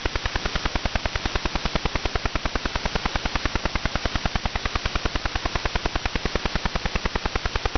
File:Chinese radar 160kHz wide AM.mp3 - Signal Identification Wiki
Chinese_radar_160kHz_wide_AM.mp3